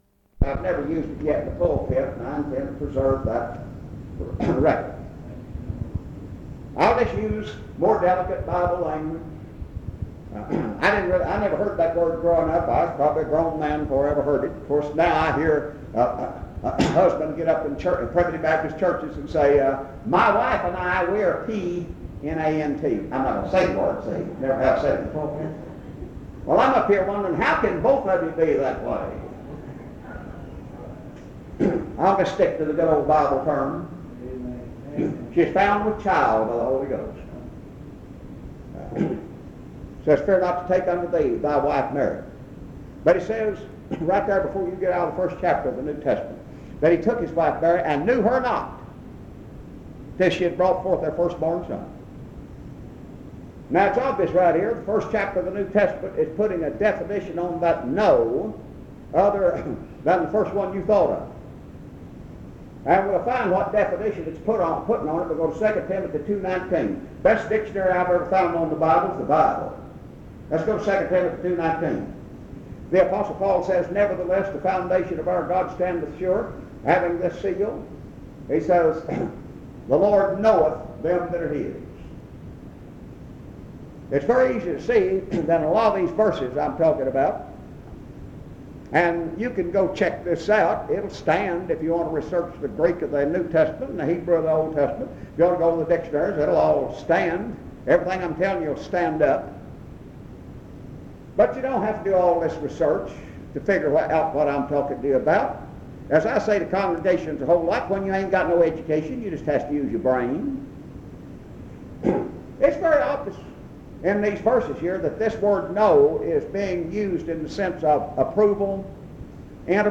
Message
at Pine Primitive Baptist Church